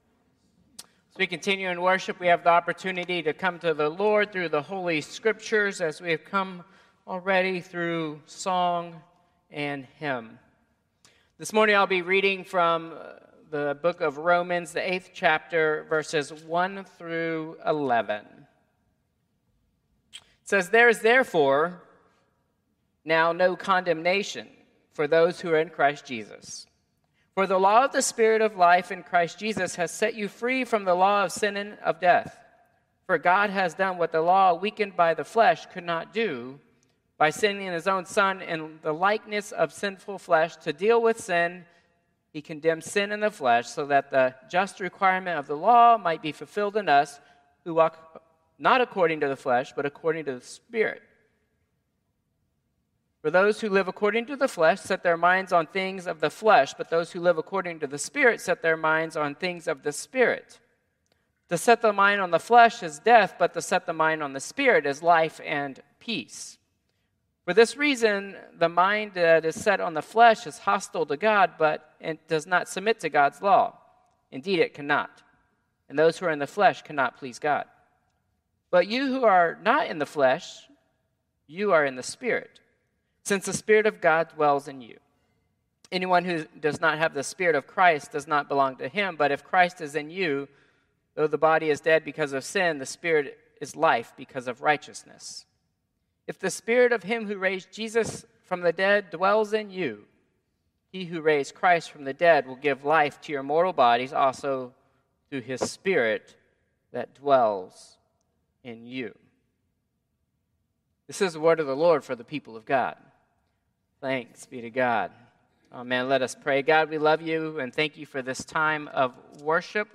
Traditional Service 6/15/2025